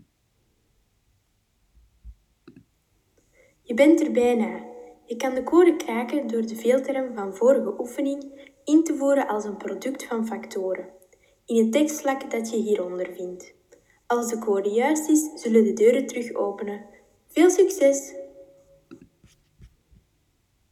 Jullie krijgen opnieuw een boodschap te horen door de luidsprekers.
Boodschap